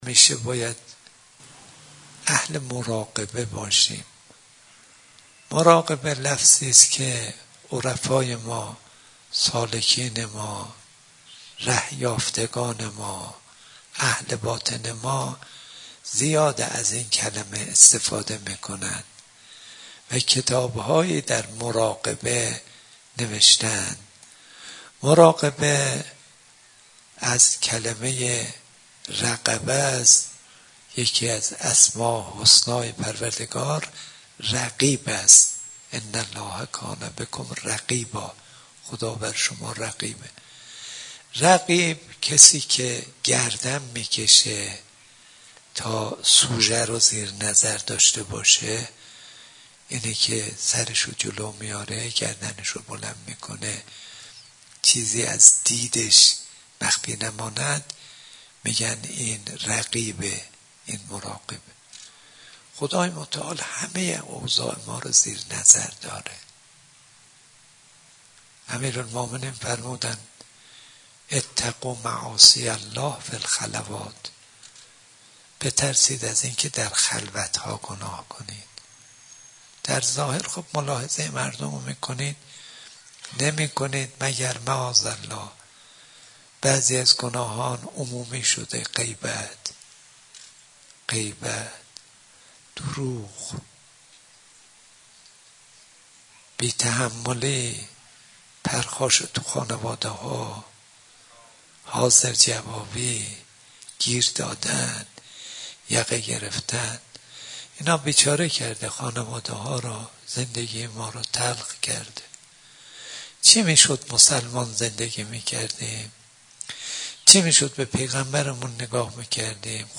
به گزارش خبرنگار خبرگزاری رسا، آیت الله صدیقی تولیت مدرسه علمیه امام خمینی(ره) شب گذشته در حسینیه صاحب الزمان(عج) به ایراد سخنرانی پرداخت و گفت: مرگ و حیات، ذلت و سعادت، شادی و گریه، بیماری و صحت همه و همه به دست خداوند است.